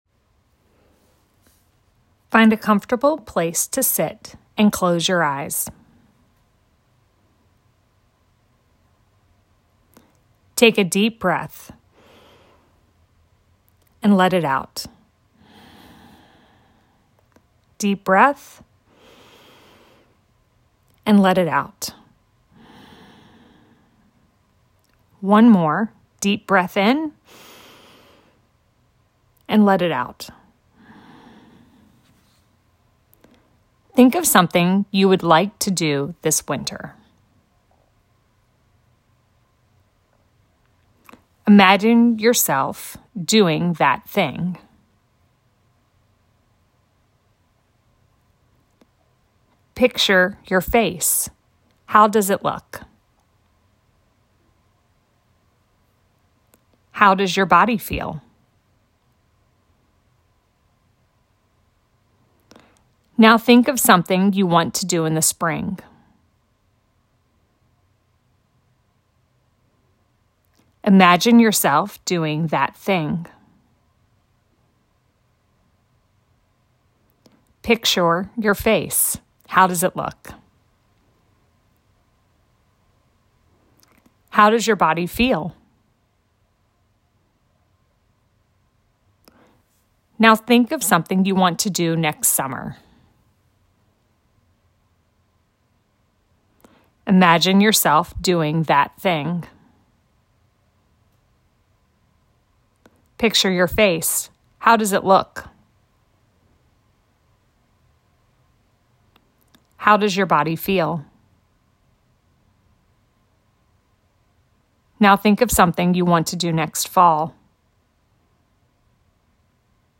a-new-years-imaginative-meditation